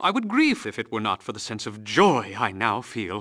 vs_fxanxxxx_haha.wav